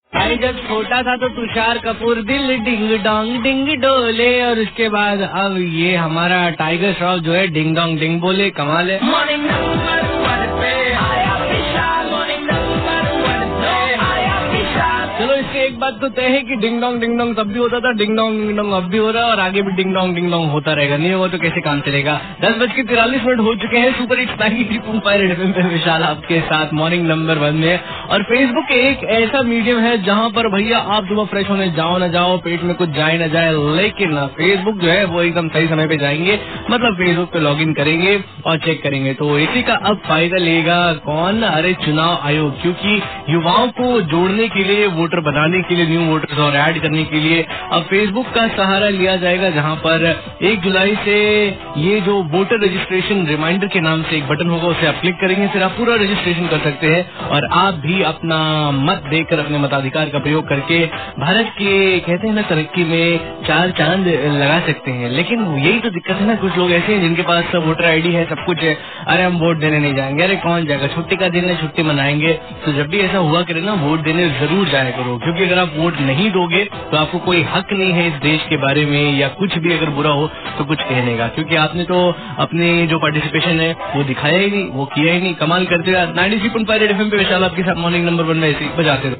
Rj about Election Commission